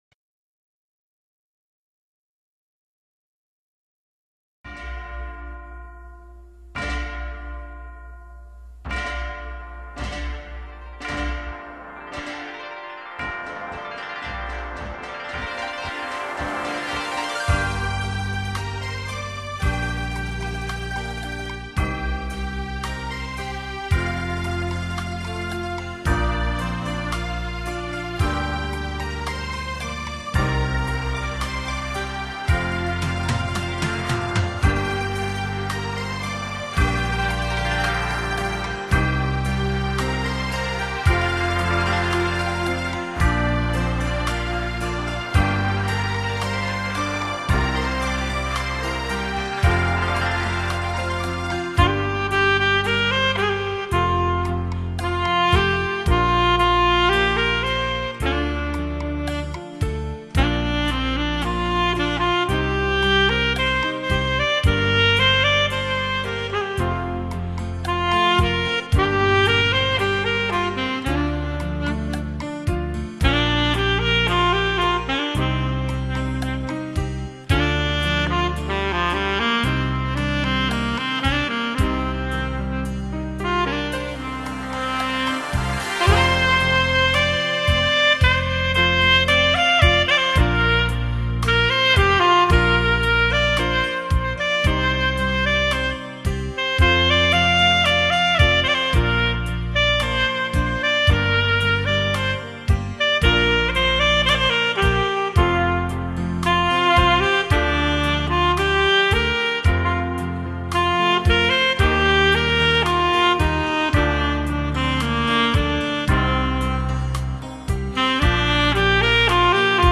萨克斯、浪漫风情的完美代言人。